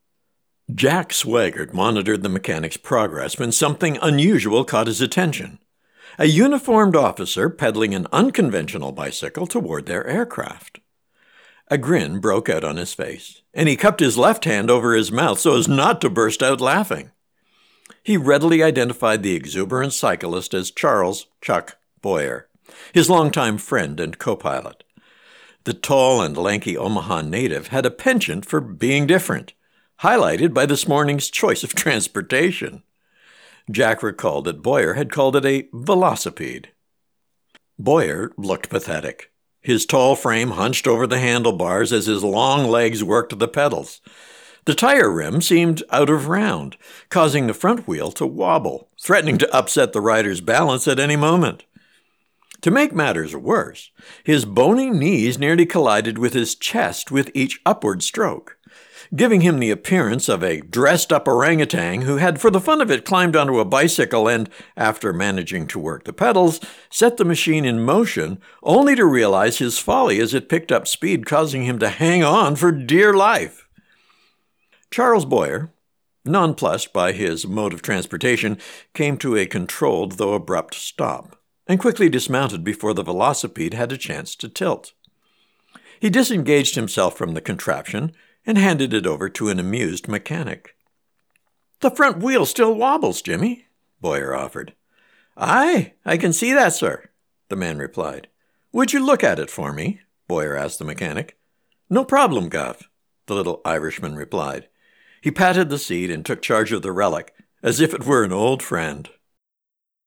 check out “Velocipede,” an audio sample from the book, with voice narration